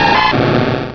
croconaw.wav